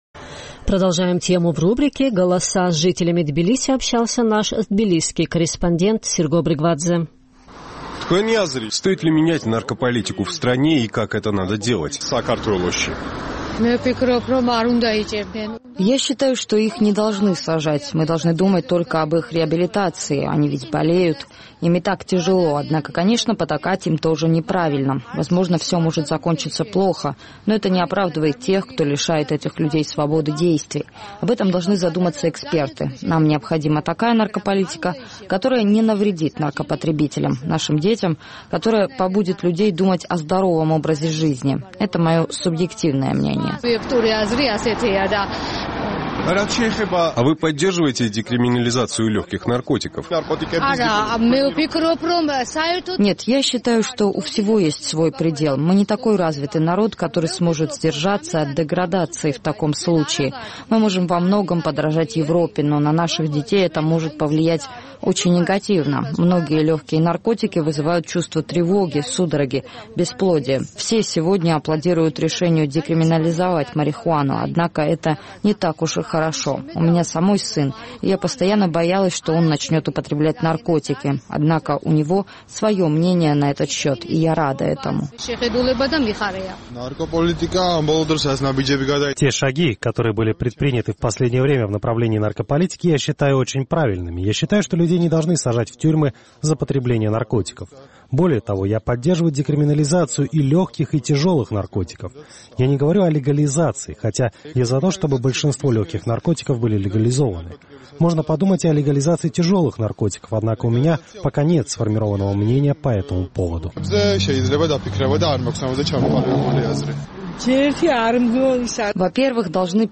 Тема разработки новой наркополитики вновь актуальна в политических и неправительственных кругах Грузии. Наш тбилисский корреспондент поинтересовался мнением рядовых граждан по этому поводу.